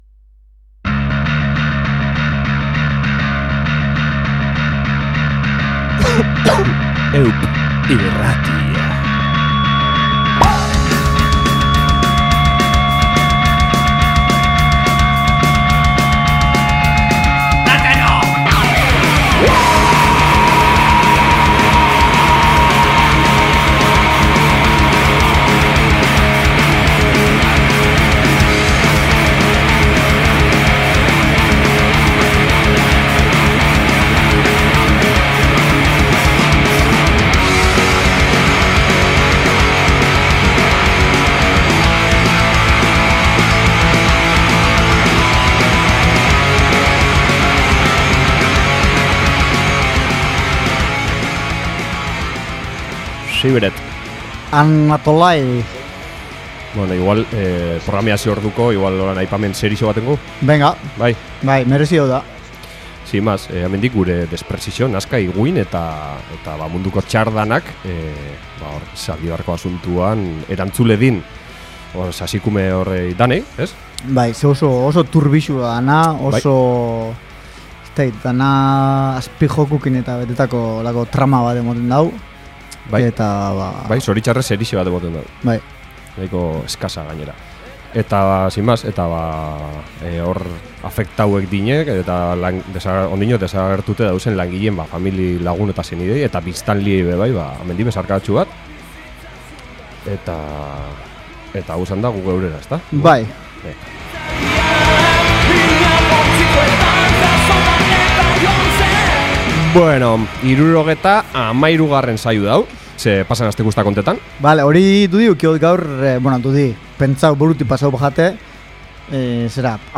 Gaur txorradak hiru ahotsetan, beste dana musikoti